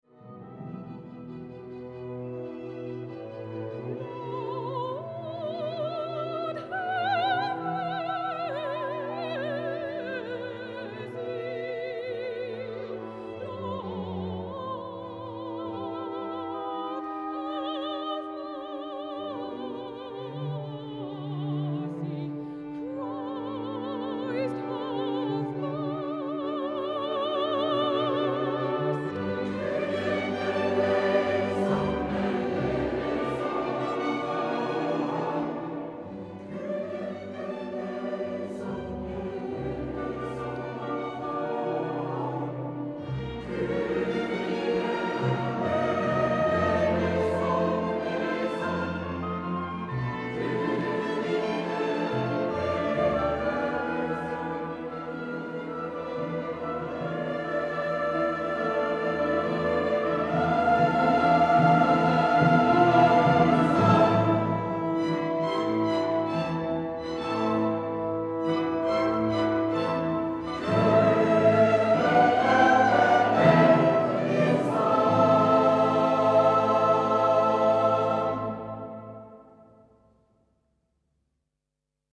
für soli, chor und kleines sinfonieorchester